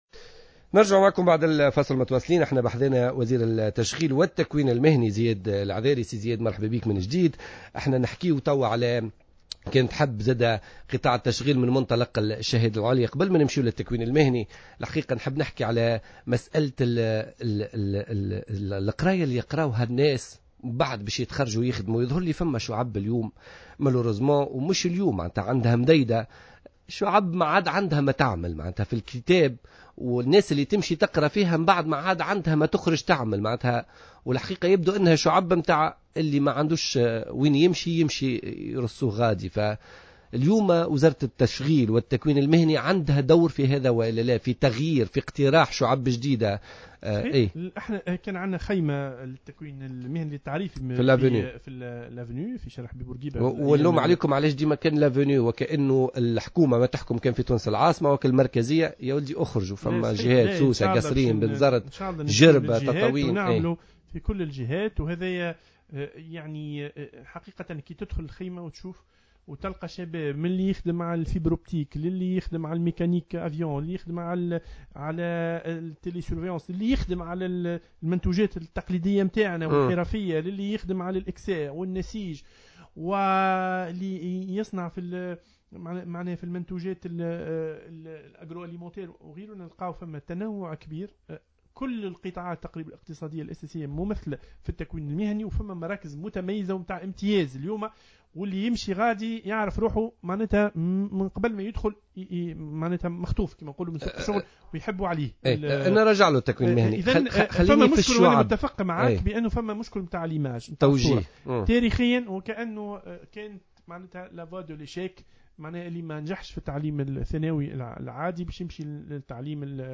أعلن وزير التشغيل والتكوين المهني زياد العذاري اليوم الاثنين 11 ماي 2015 في برنامج "بوليتيكا" على "جوهرة أف أم" أن وزارته بصدد إعداد إستراتيجية لإصلاح منظومة التكوين المهني في تونس.